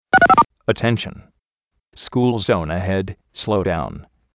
I prefer the following .wav file for the alert (although you can always make one to your own liking)
school_zone slow down.wav